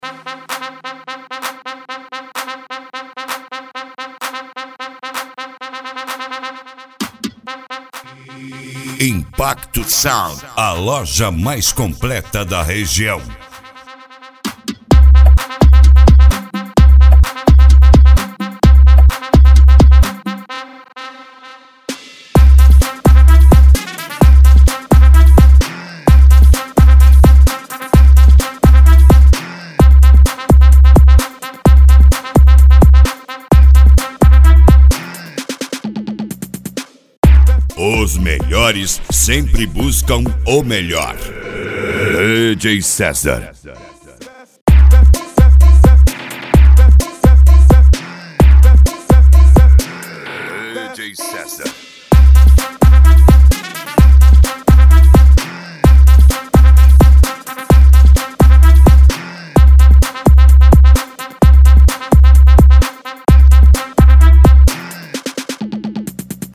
Funk Nejo
Mega Funk